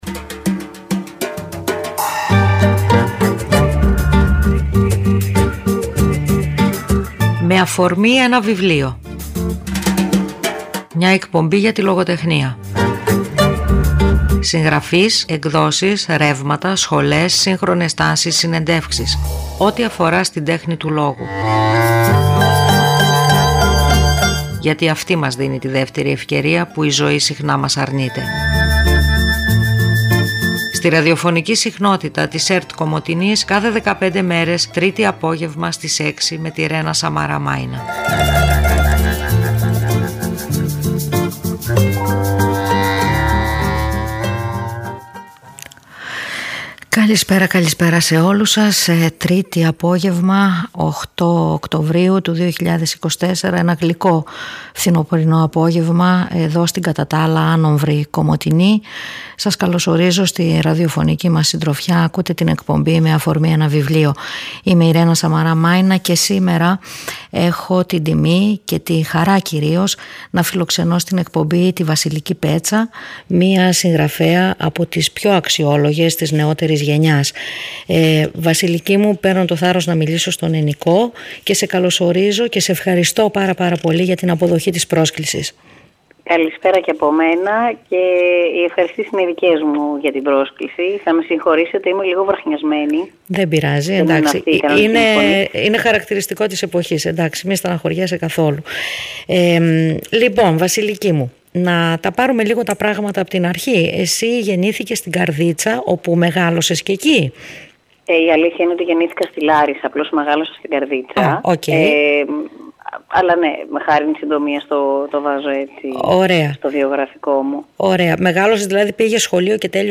Μια εκπομπή για το βιβλίο και τη λογοτεχνία. Συγγραφείς, εκδόσεις, ρεύματα, σχολές, σύγχρονες τάσεις, συνεντεύξεις.